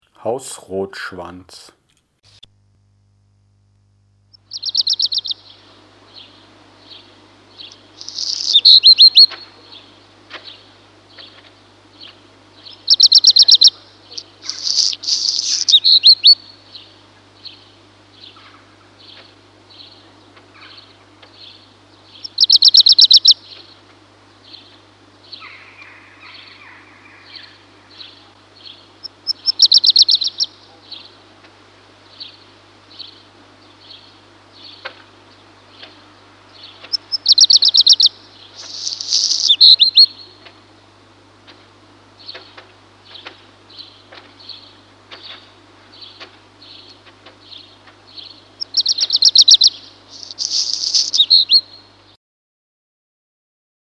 für alle sind Gesänge bzw. Stimmen abrufbar
Hausrotschwanz
hausrotschwanz.mp3